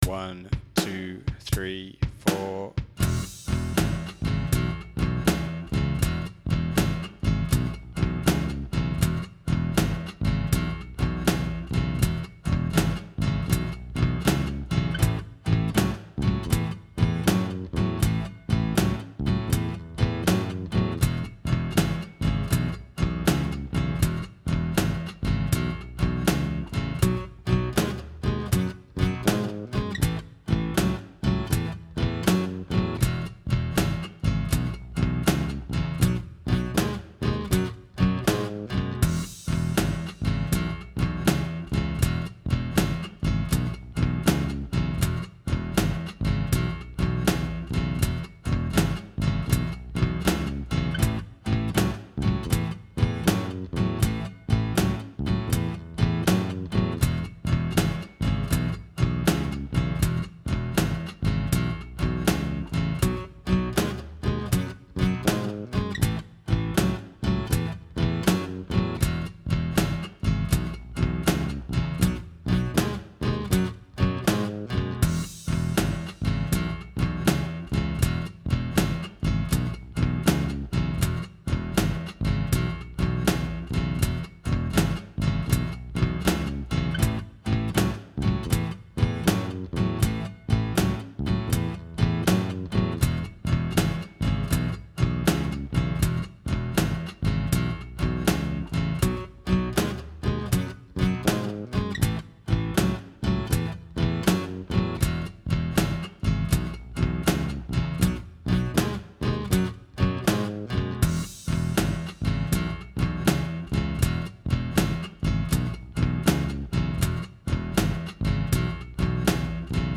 12 Bar Blues in E Backing Track | Download
bluese_jam.mp3